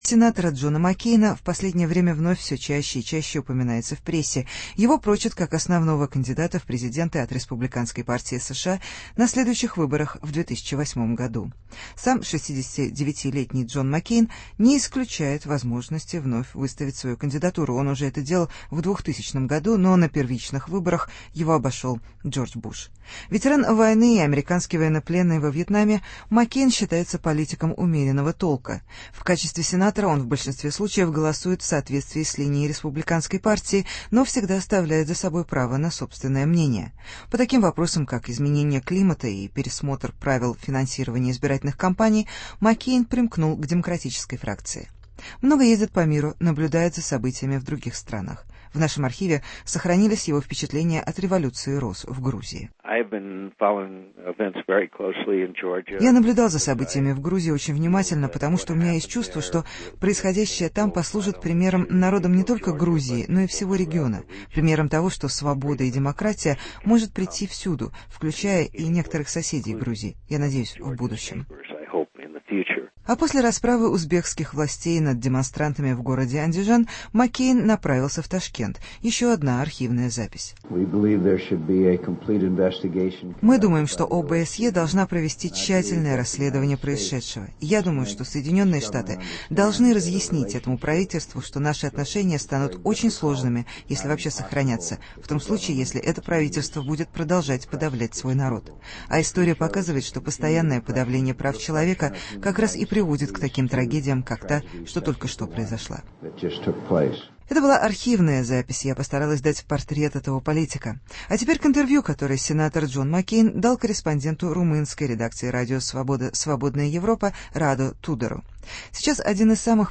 Интервью с сенатором США Джоном Маккейном